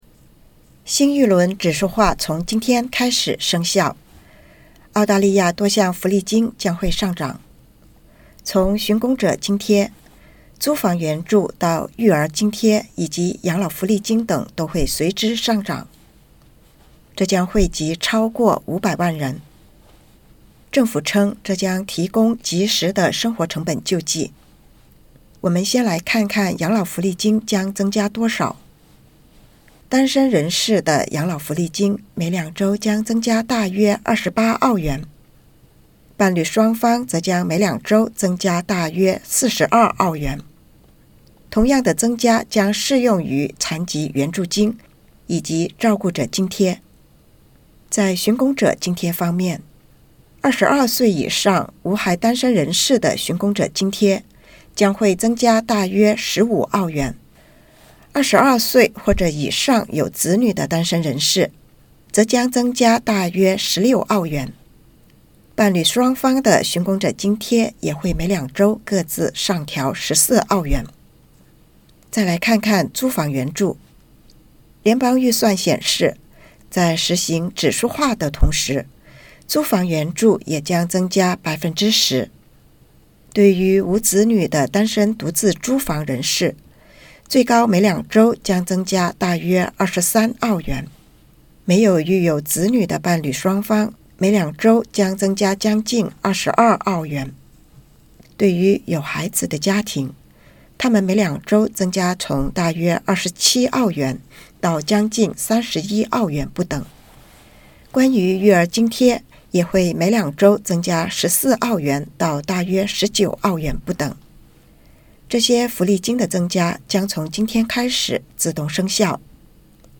最新的指数化今（9月20日）起生效，澳大利亚多项政府福利金的金额，包括寻工者津贴、租房援助、育儿津贴和养老福利金，都将随之上涨，这将惠及500多万澳大利亚人。请点击播放键收听报道。